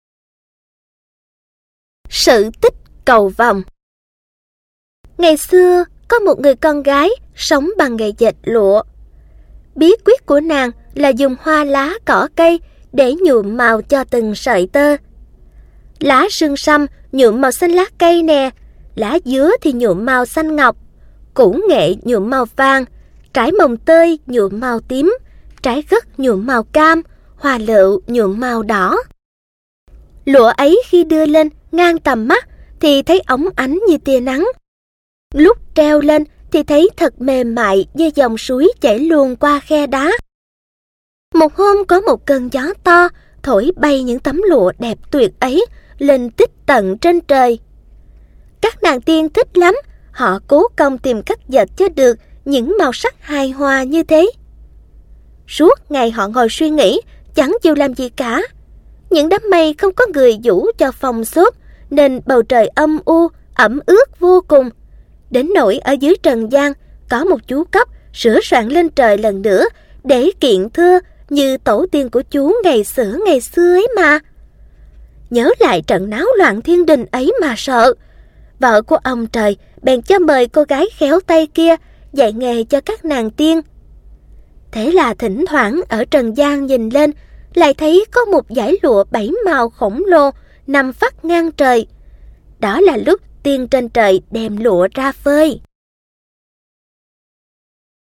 Sách nói | Xóm Đồ Chơi P19